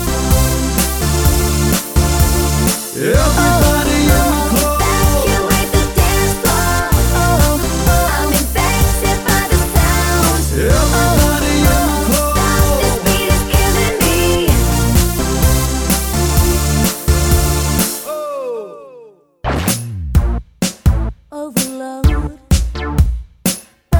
Without Rap Dance 3:27 Buy £1.50